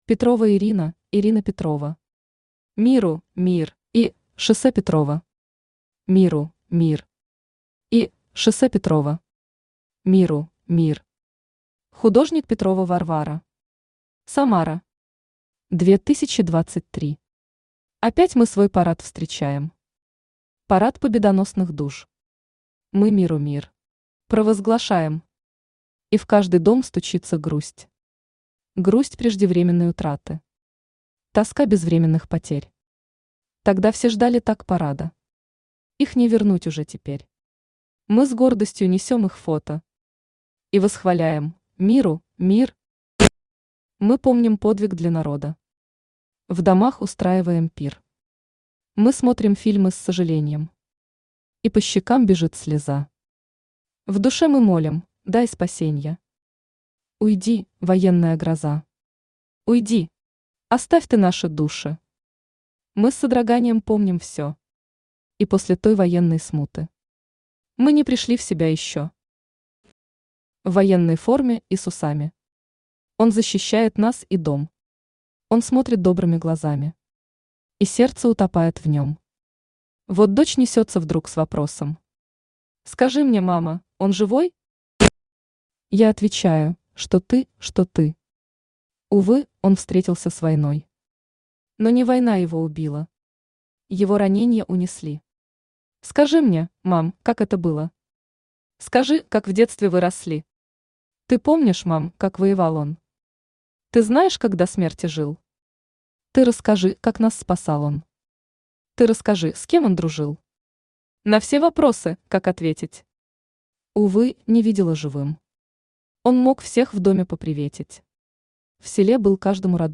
Аудиокнига Миру – мир!
Автор Петрова Шамильевна Ирина Читает аудиокнигу Авточтец ЛитРес.